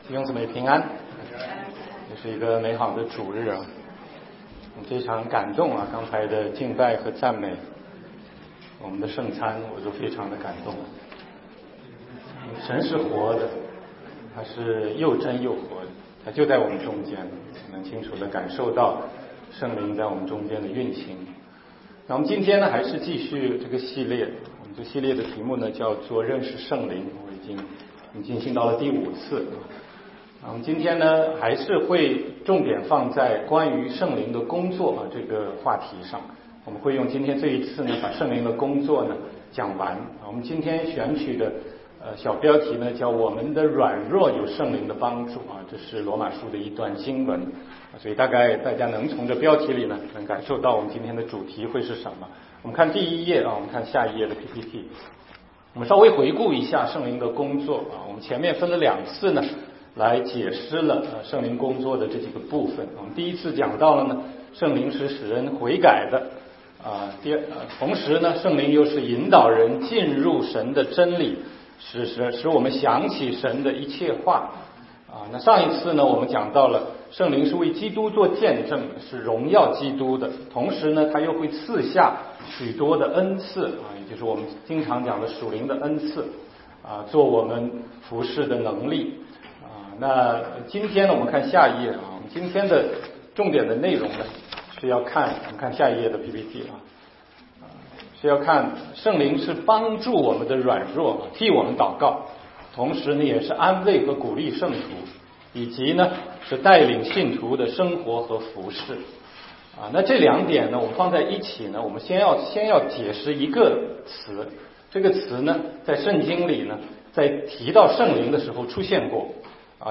16街讲道录音 - 认识圣灵系列之五：我们的软弱有圣灵帮助